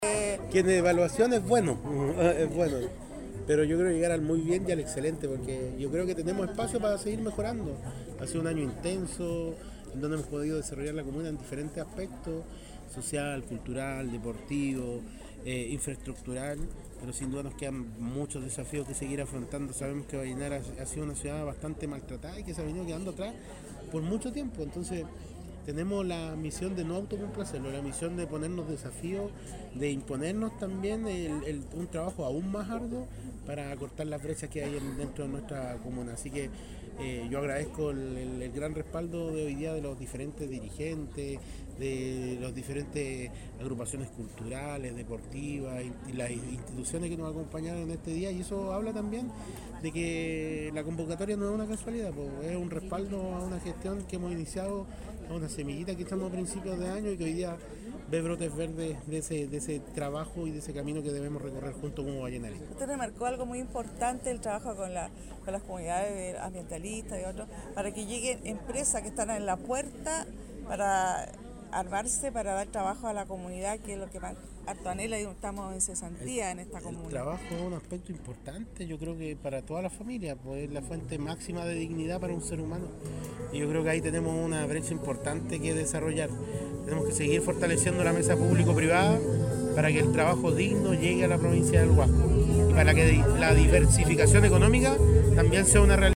Masiva celebración de “Navidad Atacameña” reunió a familias en la Plaza Ambrosio O`Higgins de Vallenar
Durante la jornada, el alcalde de Vallenar Víctor Isla Lutz destacó el primer año de gestión municipal, valorando el trabajo conjunto con organizaciones sociales, culturales y deportivas, y reafirmó el compromiso de seguir avanzando en el desarrollo social, cultural, económico e infraestructural de la comuna, poniendo énfasis en la generación de empleo y la diversificación económica en la provincia del Huasco.